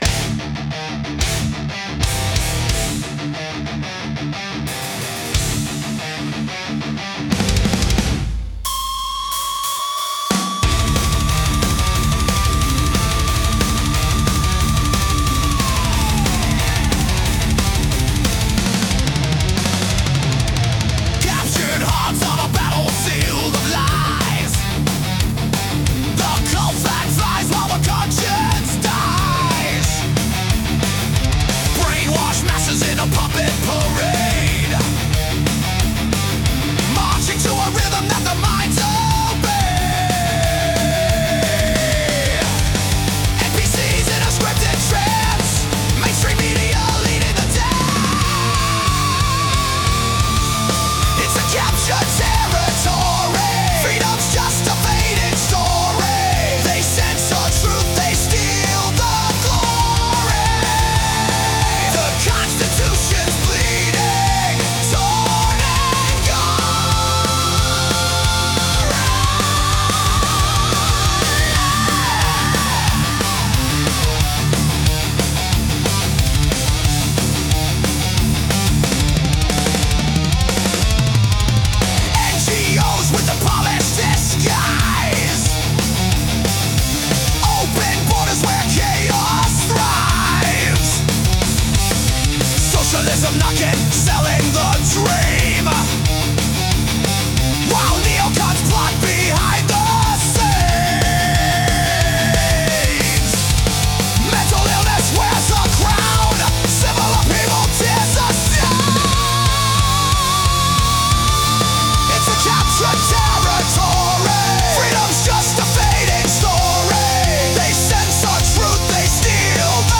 hard core